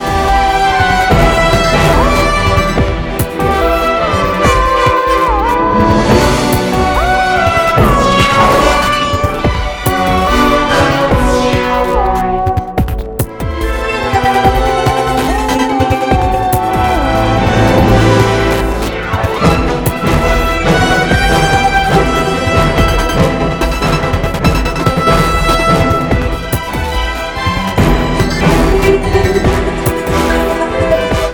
• Качество: 192, Stereo
громкие
Electronic
эпичные
Эпичная и трогательная композиция